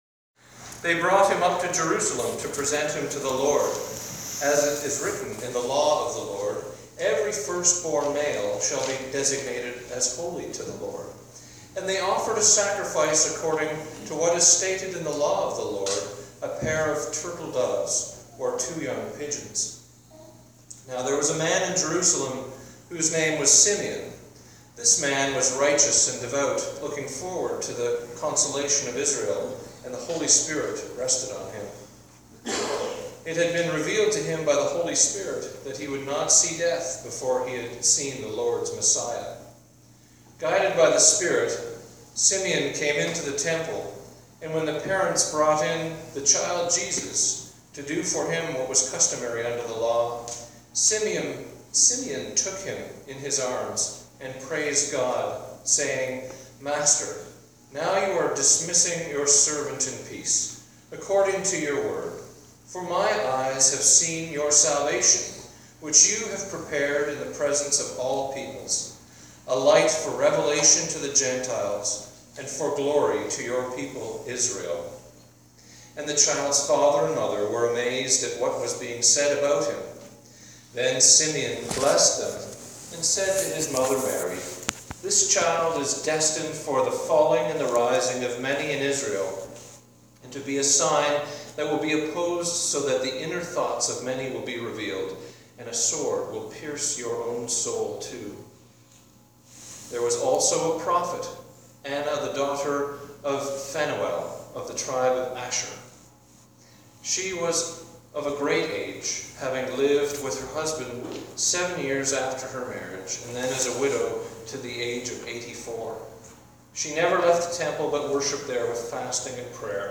Today’s Sermon – Preceded by the Gospel Reading CHRISTMAS 1